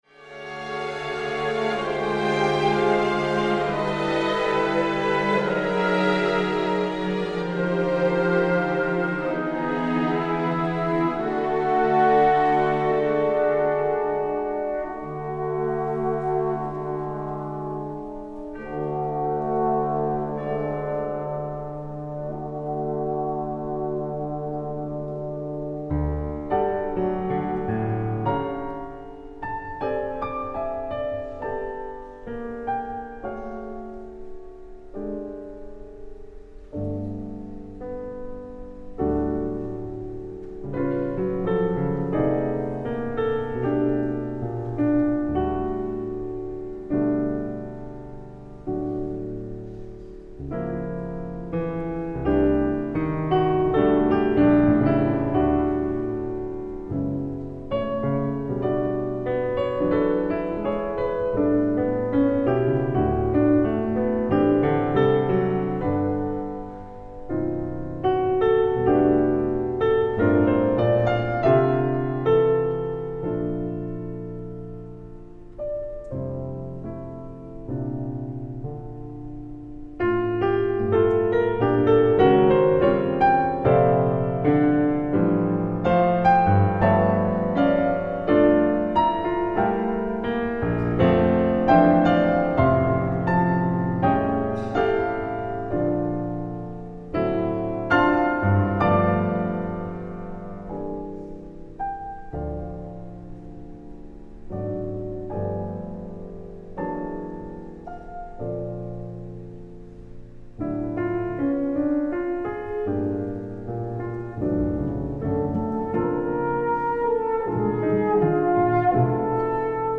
ライブ・アット・ルントフンクハウス、ベルリン、ドイツ 06/14/2025
※試聴用に実際より音質を落としています。